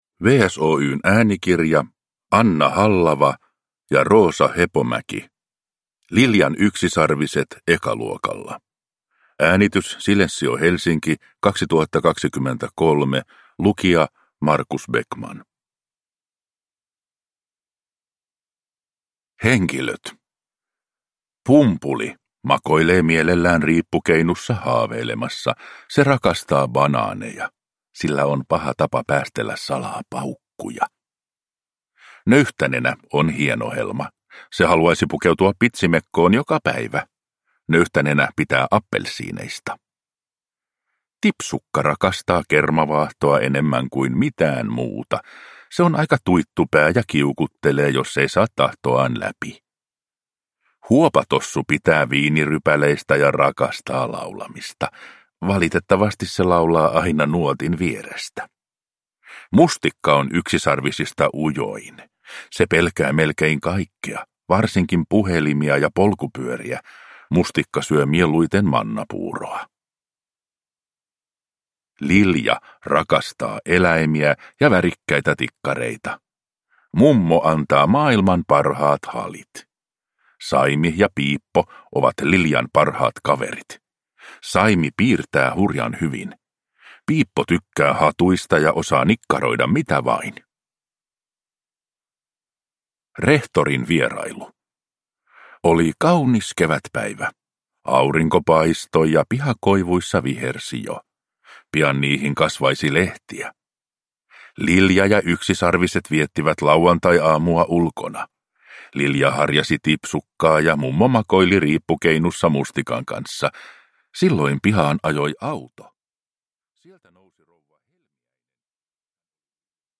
Liljan yksisarviset ekaluokalla – Ljudbok – Laddas ner